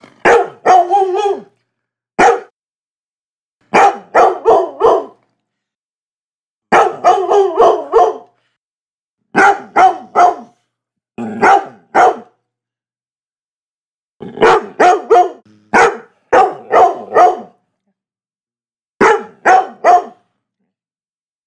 جلوه های صوتی
دانلود صدای پارس سگ از ساعد نیوز با لینک مستقیم و کیفیت بالا